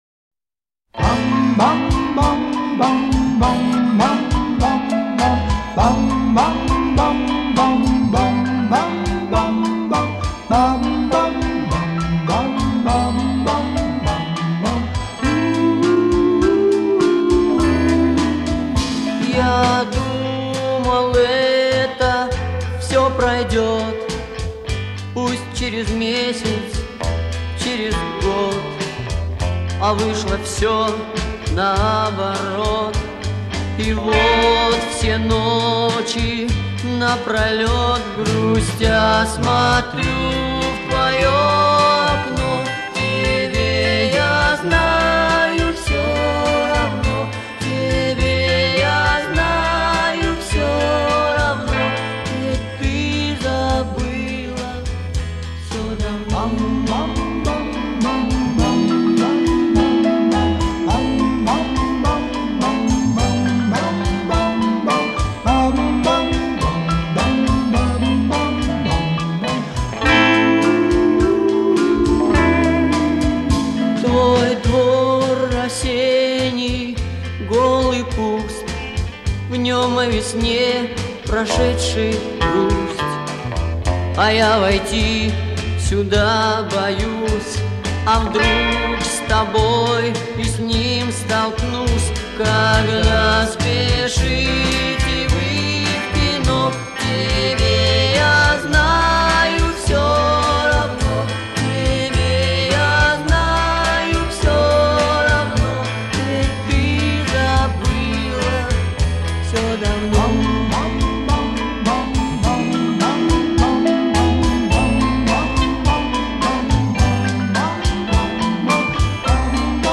Мелодичная и напевная песня школьных лет.
Замечательно раскладывается на голоса.